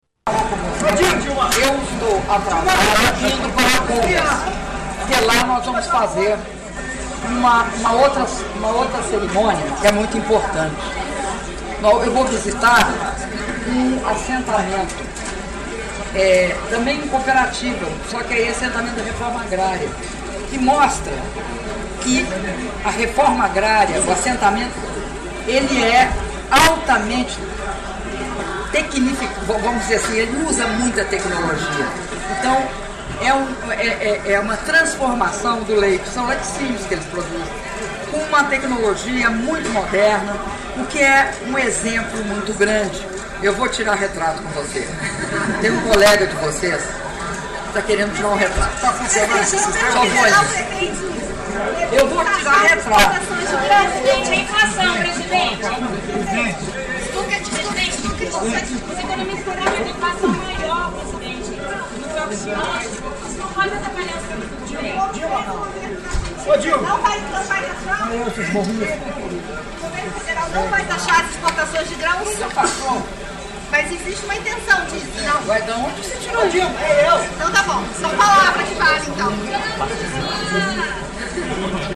Entrevista coletiva concedida pela Presidenta da República, Dilma Rousseff, após cerimônia de entrega de 29 máquinas retroescavadeiras a municípios do estado do Paraná
Cascavel-PR, 04 de fevereiro de 2013